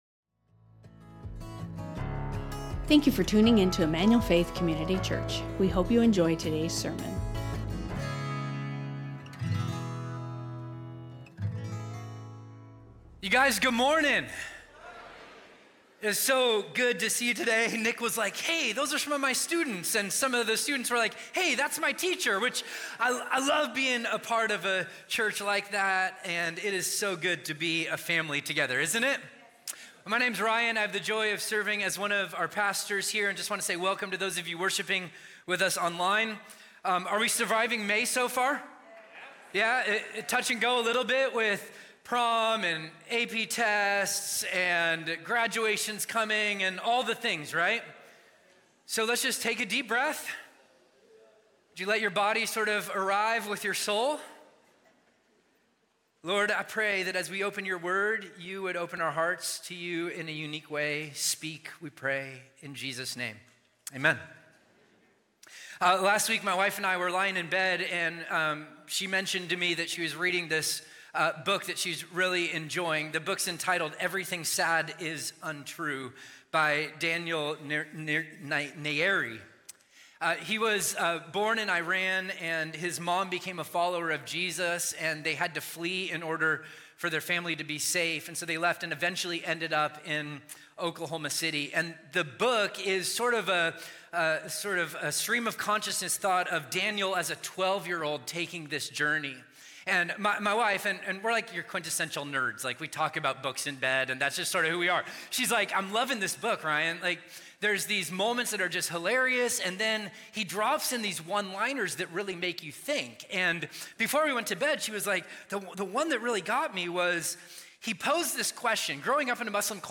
Emmanuel Faith Sermon Podcast A Letter to Tolerant People | Revelation 2:18-29 May 19 2025 | 00:45:44 1x 00:00 / 00:45:44 Subscribe Share Spotify Amazon Music RSS Feed Share Link Embed